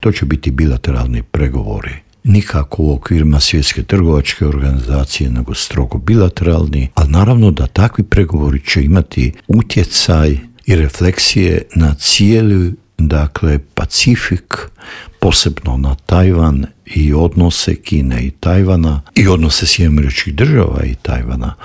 ZAGREB - Dinamična politička zbivanja u svijetu tema su i novog Intervjua Media servisa.